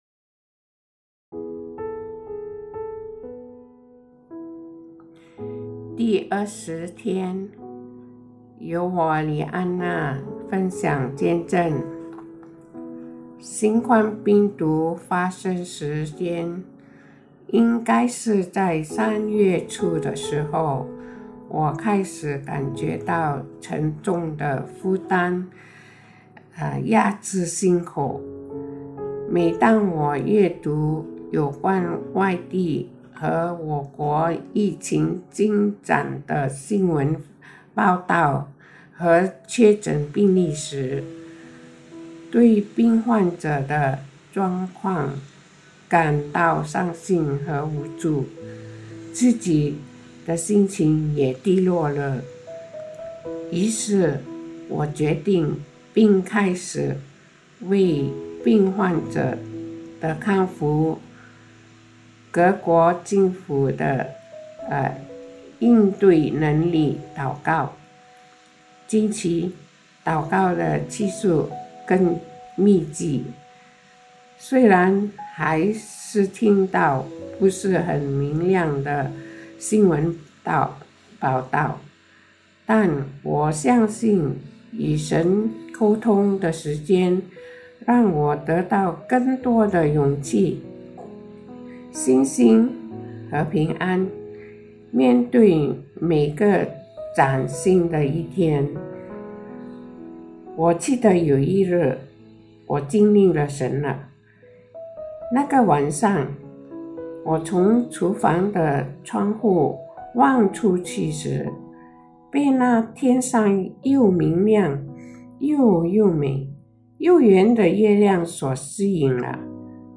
见证分享